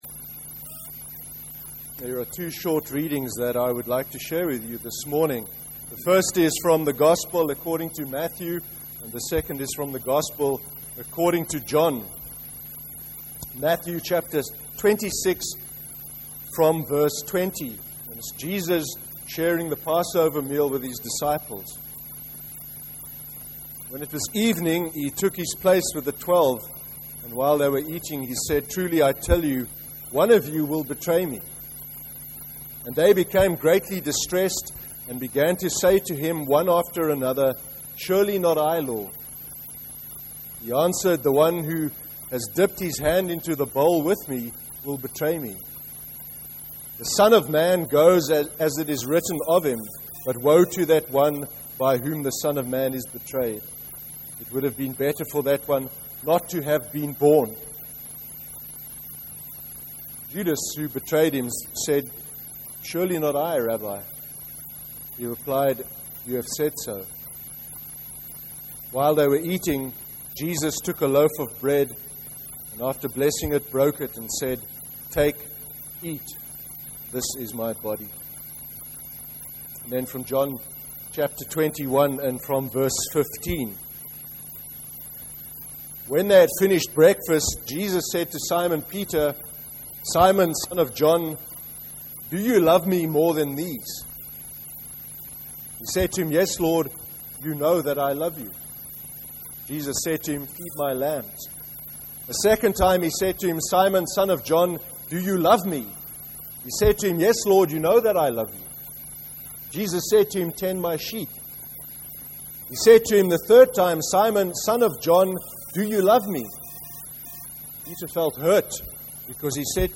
06/07/2014 sermon. Faithfulness. (Matthew 26:20-26, John 21:15-17)